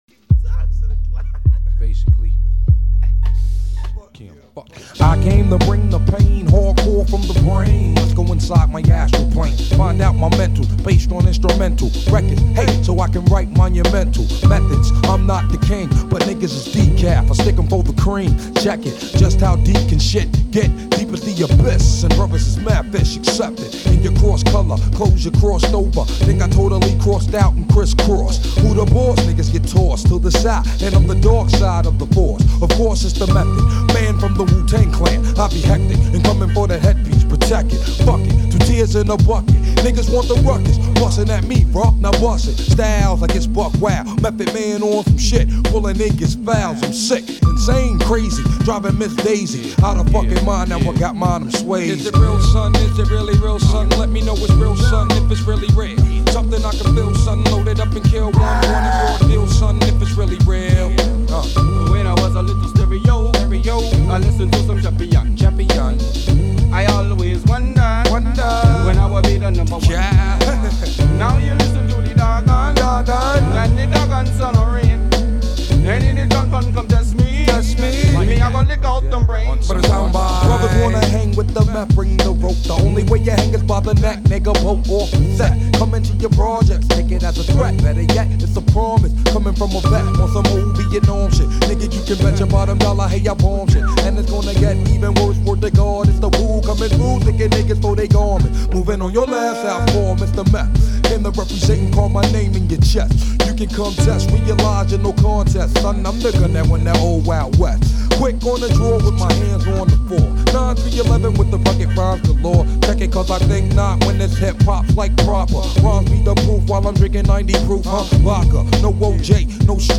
Назад в (rap)...
Файл в обменнике2 Myзыкa->Рэп и RnВ